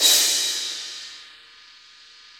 Index of /90_sSampleCDs/Sound & Vision - Gigapack I CD 1 (Roland)/CYM_K-CRASH st/CYM_K-Crash st 2
CYM CRA3305R.wav